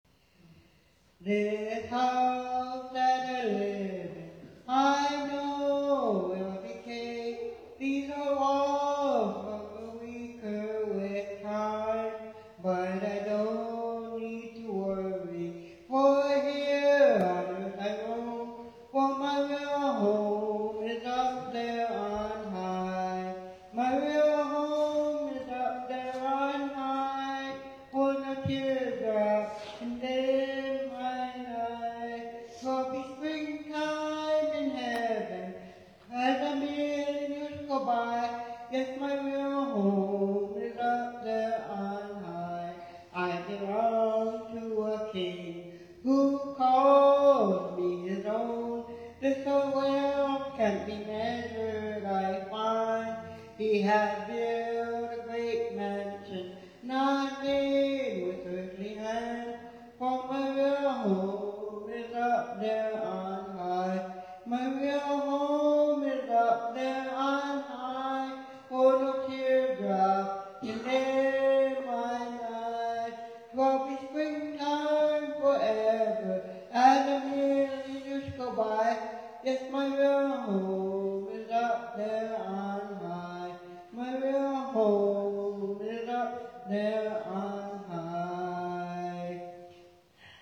From her seat in the center of the hall
gospel song “My Real Home